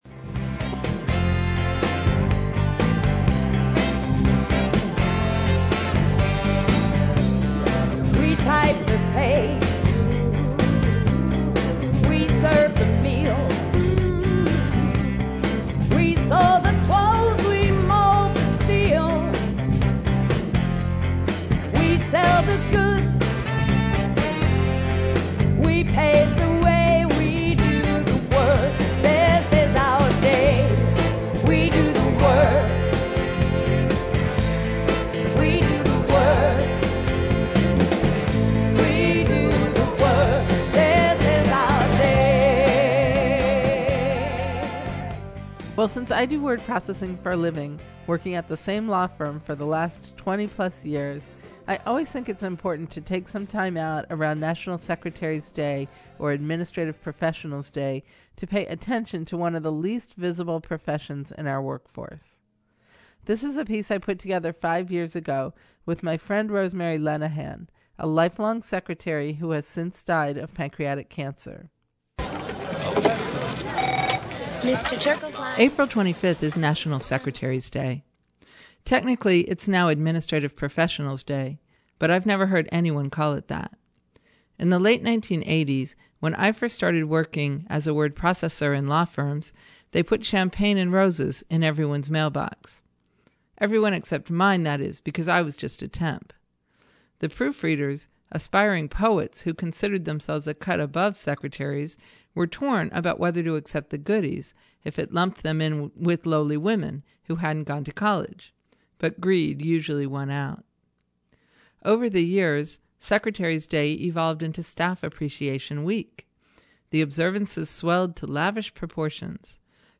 Clerical and administrative workers are still the most heavily female work force in the country. In observation of Secretaries' Day ( Administrative Professionals Day ), we listen to the voices of workers who are seldom heard. 20:44 min.